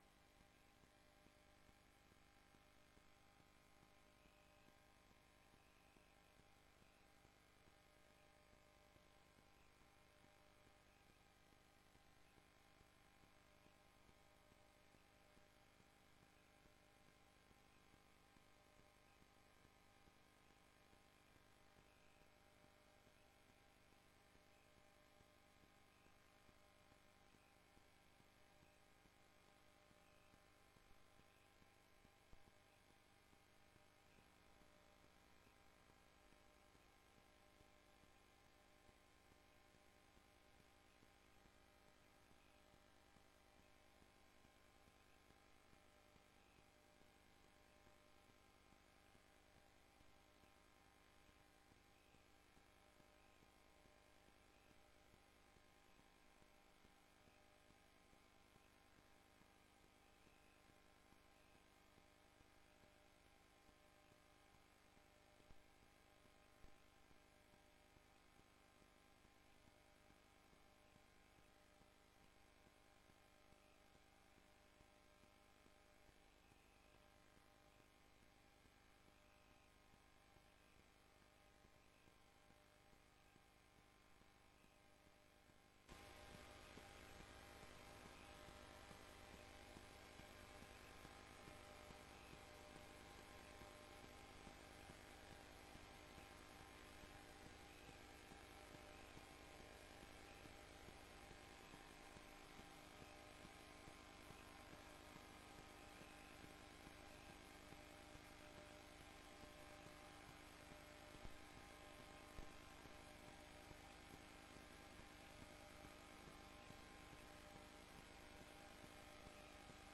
Informerende raad 'Subsidiebeleid'.Locatie: raadzaal